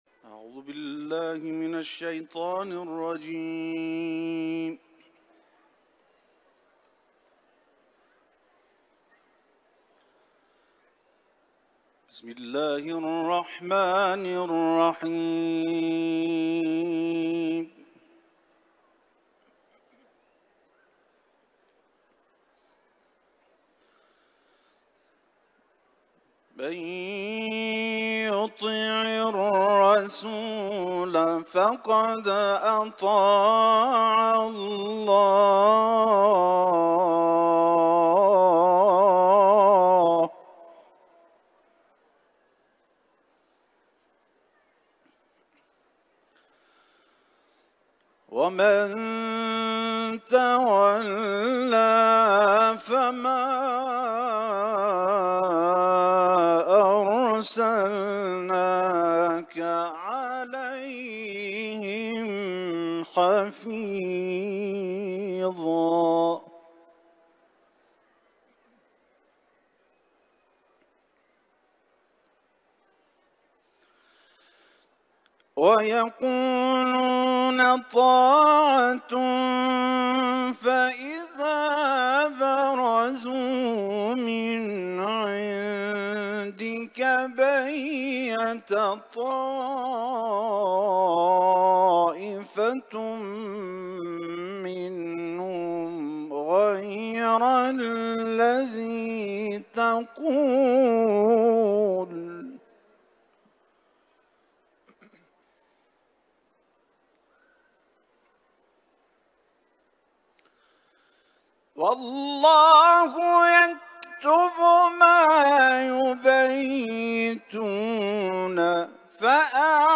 Etiketler: kuran ، tilavet ، İranlı kâri ، İmam rıza türbesi